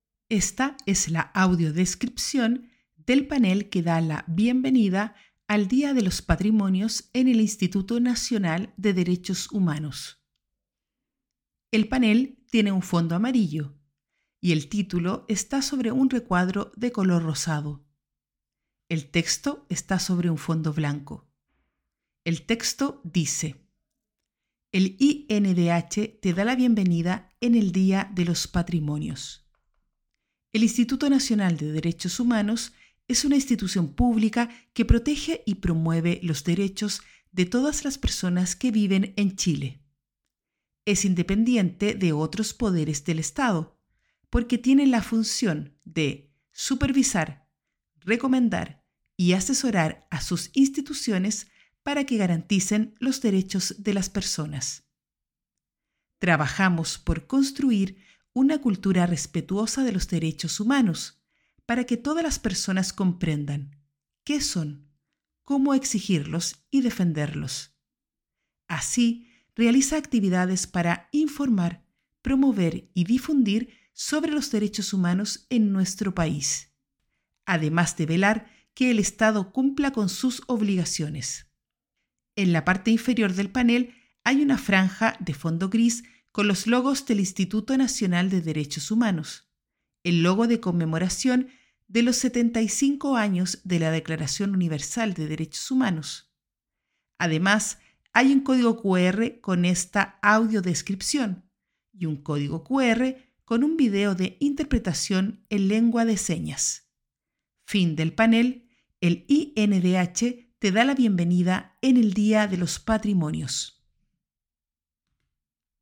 audiodescripcion-panel-de-bienvenida.mp3